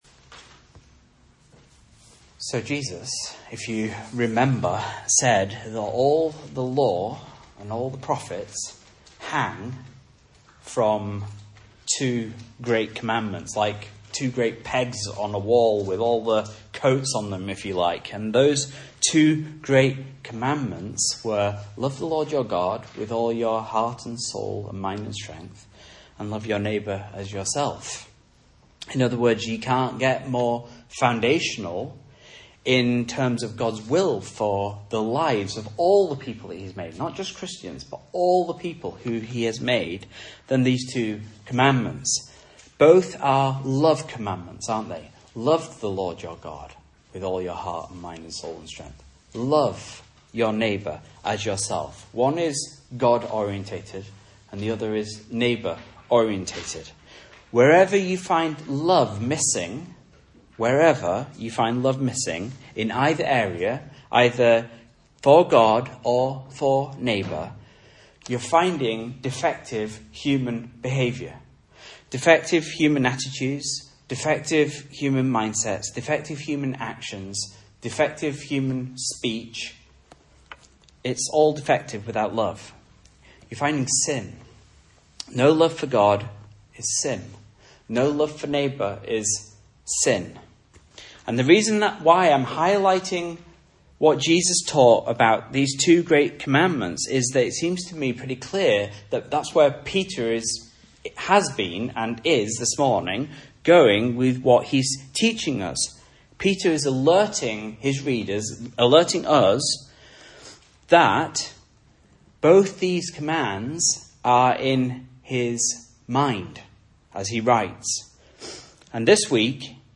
Message Scripture: 1 Peter 1:22-25 | Listen